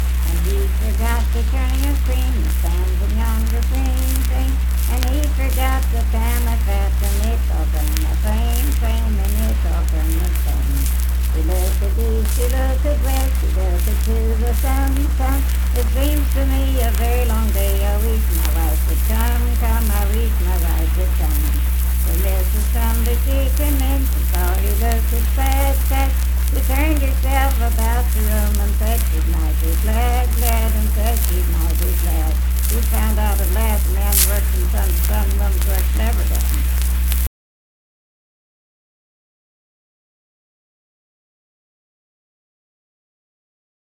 Unaccompanied vocal music performance
Miscellaneous--Musical
Voice (sung)